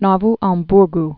(nôv äɴm-brg)